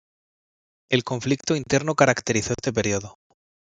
Pronounced as (IPA) /inˈteɾno/